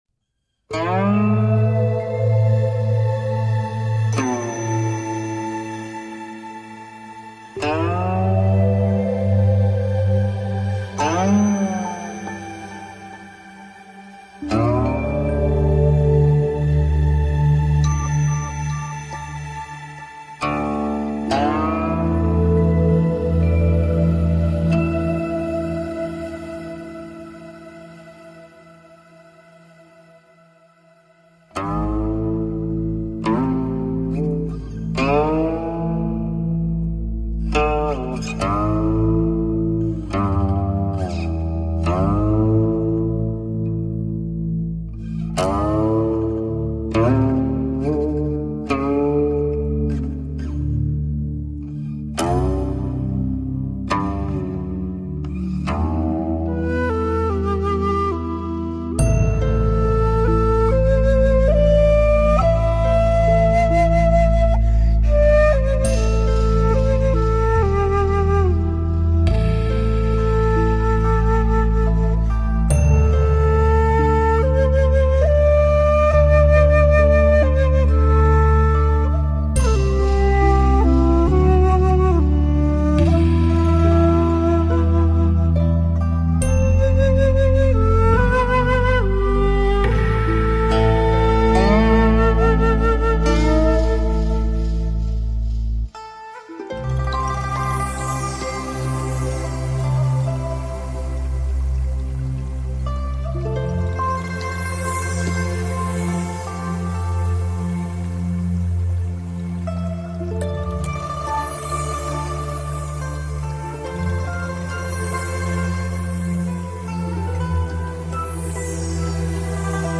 3周前 纯音乐 7